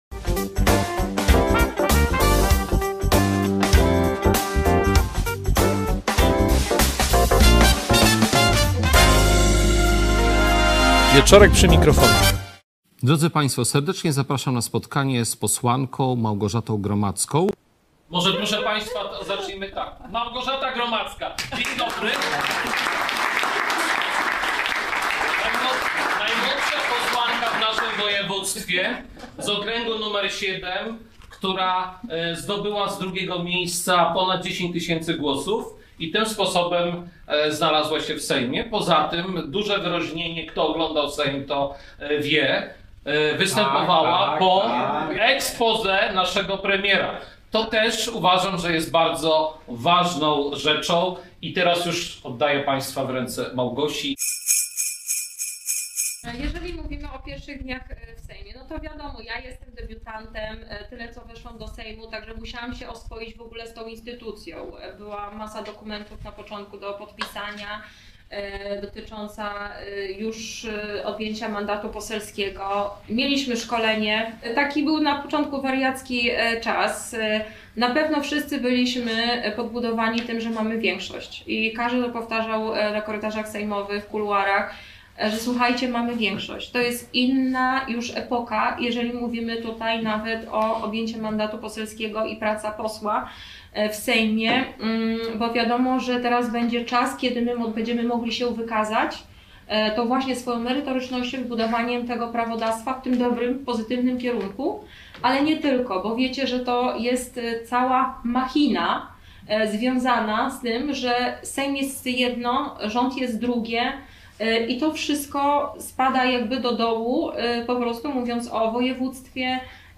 Spotkanie z posłanką Małgorzatą Gromadzką (PO), które odbyło się w Lublinie, 14 grudnia 2023 r.